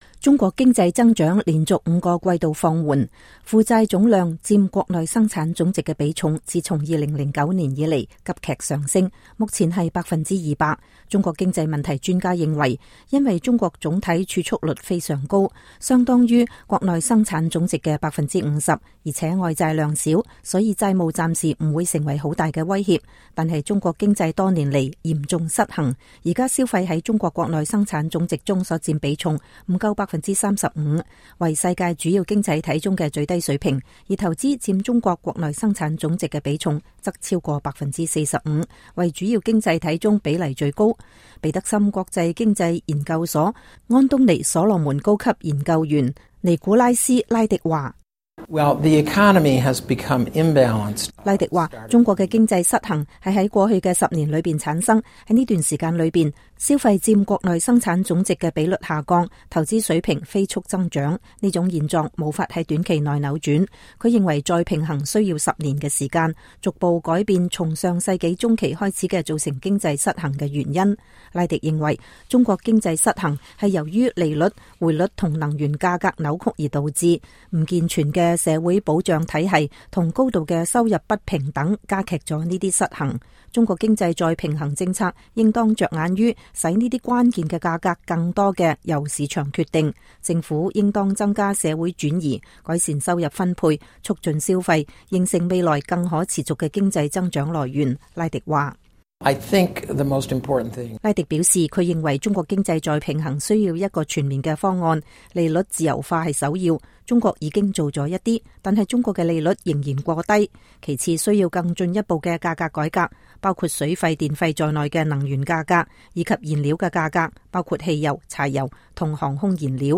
中共十八屆三中全會即將召開，中國領導人屆時會推出那些經濟改革政策，中國是否能在未來十年裡使經濟走上更可持續的增長路徑，實現經濟再平衡，令人拭目以待。在這集的報道中，我們邀請了專家來分析中國如何走出債務困境，避免危機，實現經濟平衡。